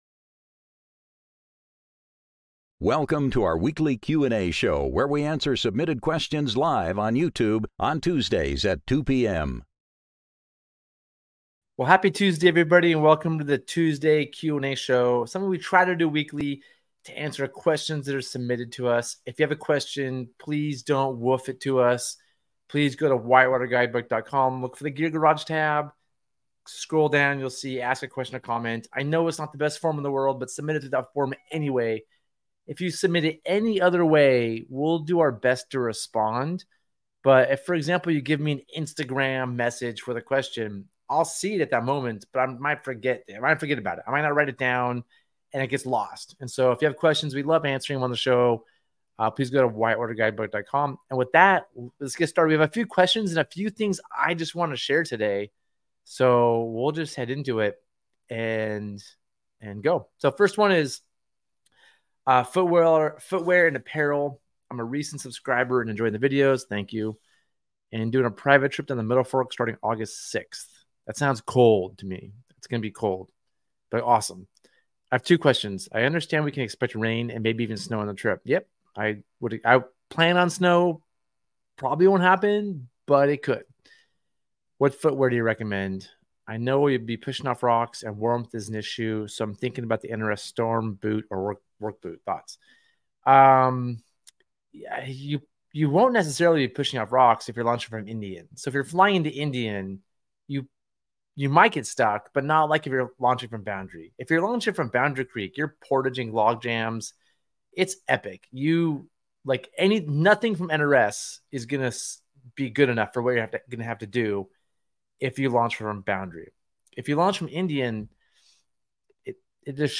Gear Garage Live Show Gear Garage Q & A Tuesday Show | August 29th, 2023 Aug 30 2023 | 00:50:49 1x 00:00 / 00:50:49 Subscribe Share Spotify RSS Feed Share Link Embed